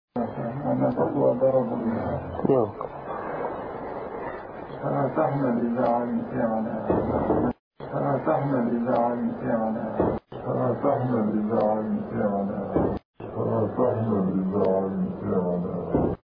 2002年1月に公表されたビン・ラディンのアラビア語のスピーチの裏では、